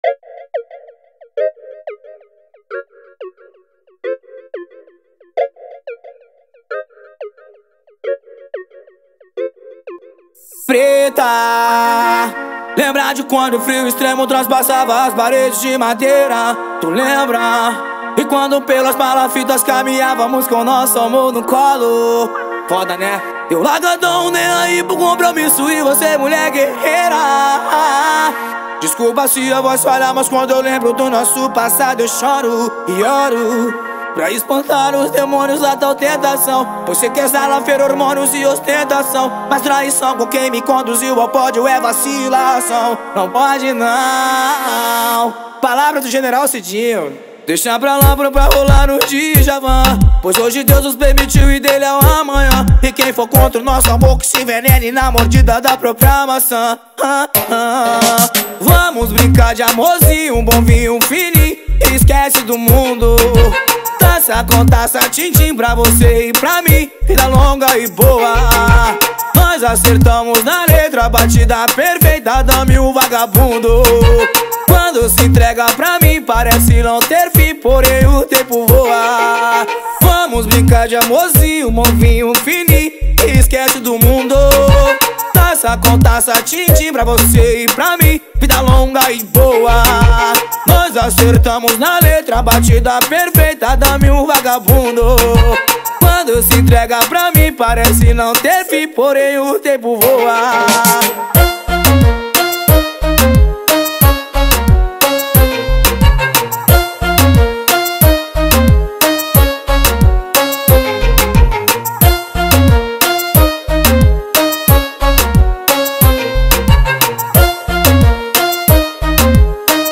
2024-07-24 15:51:37 Gênero: Funk Views